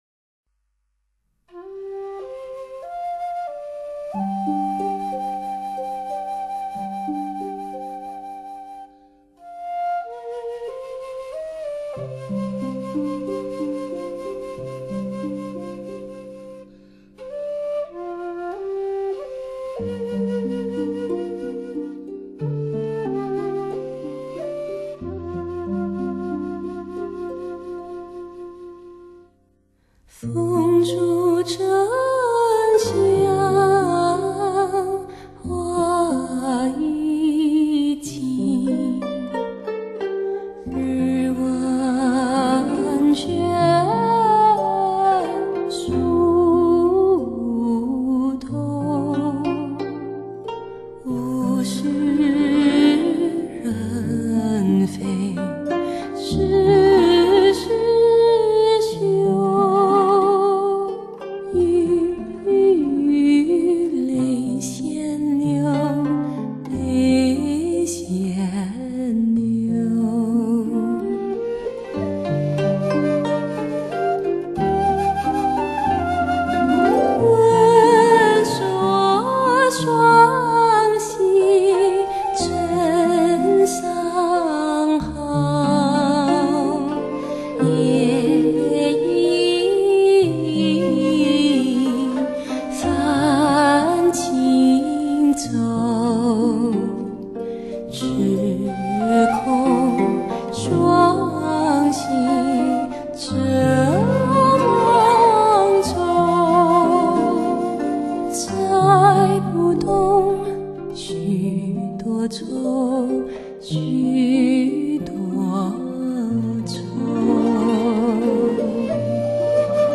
由于该碟编曲和演唱方面都非常抒情所以很适合闲时聆听。